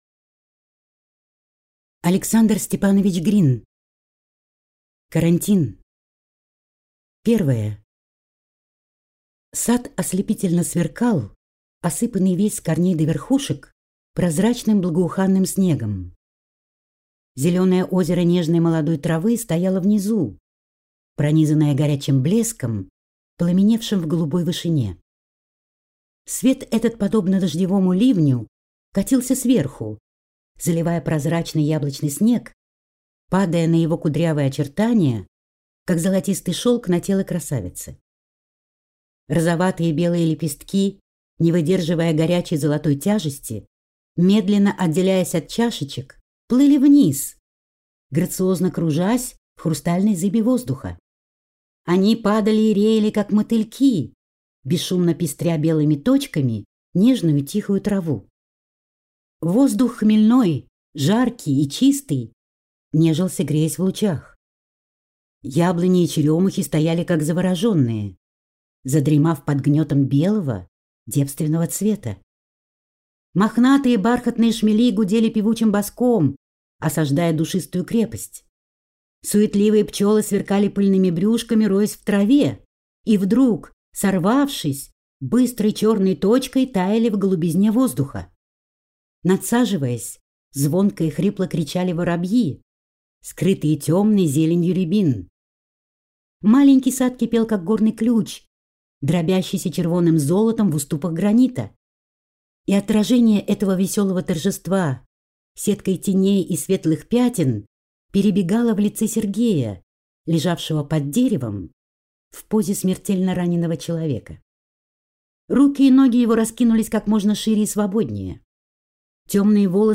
Аудиокнига Карантин | Библиотека аудиокниг